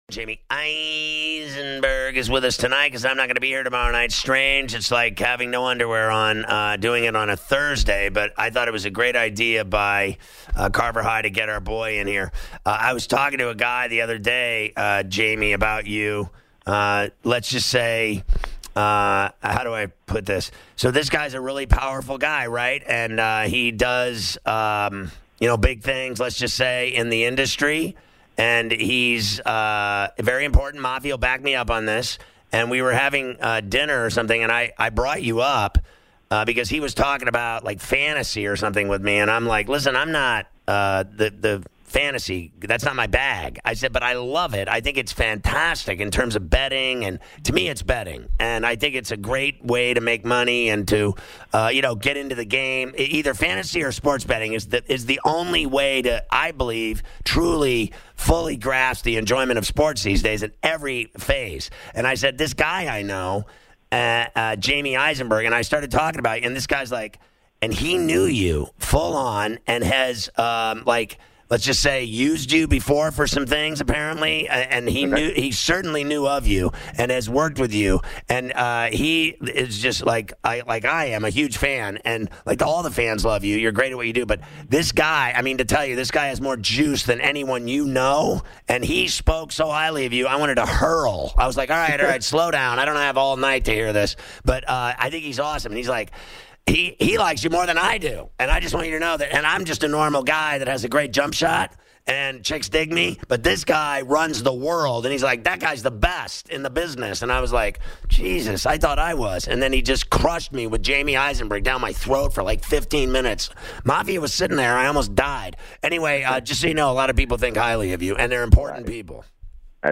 take the fans calls on their Fantasy Football playoff rosters
Interview